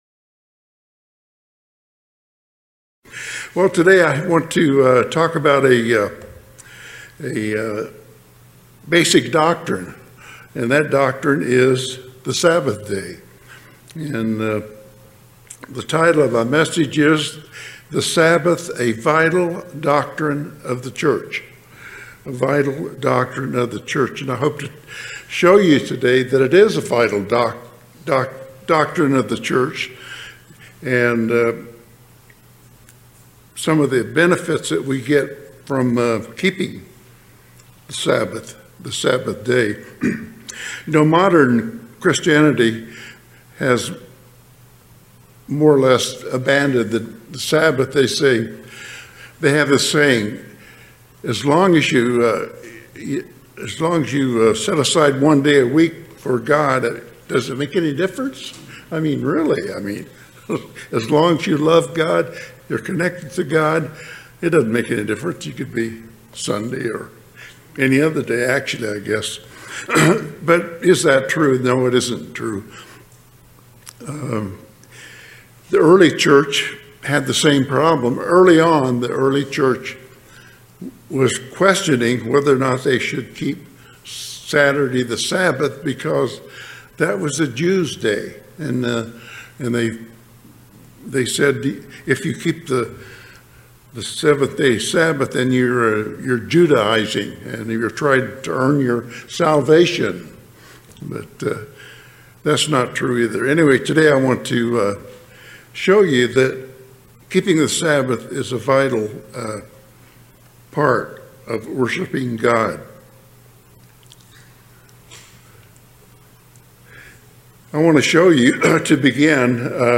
Sermons
Given in Las Vegas, NV